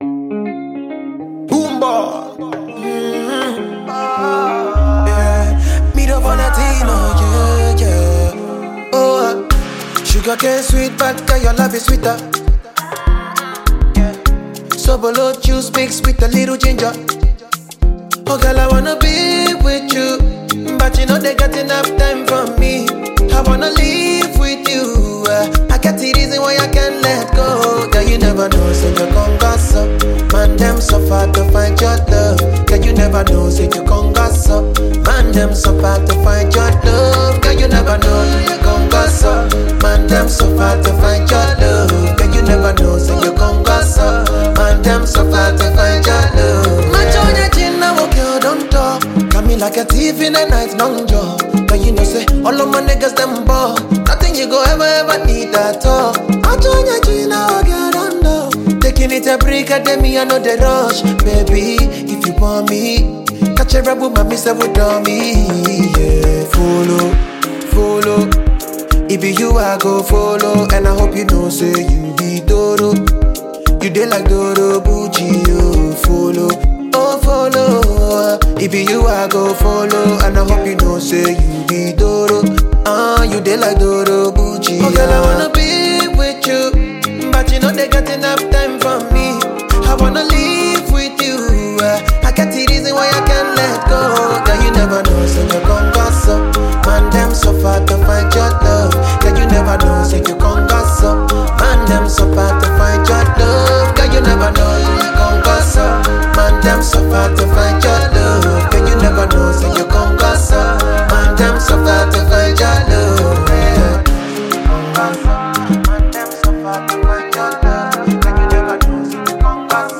Ghanaian afrobeat musician